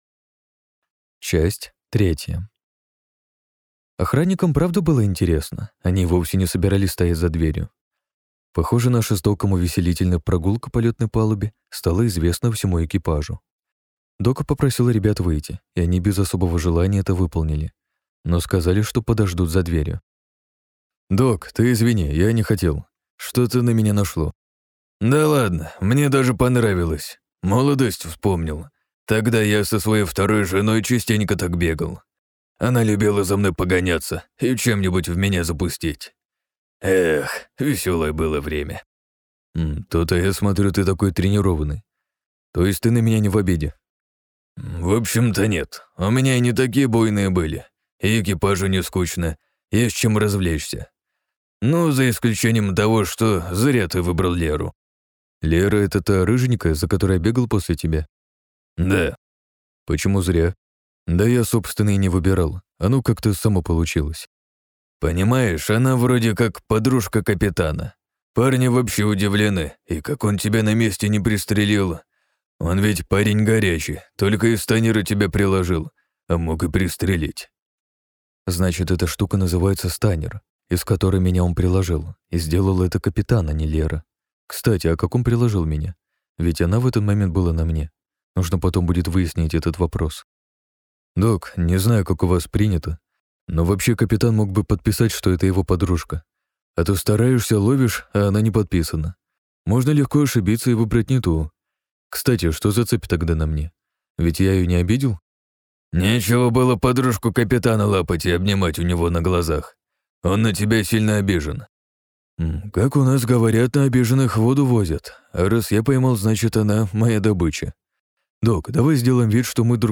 На границе империй. Книга 1 - INDIGO. Аудиокнига - слушать онлайн